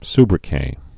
(sbrĭ-kā, -kĕt, sbrĭ-kā, -kĕt)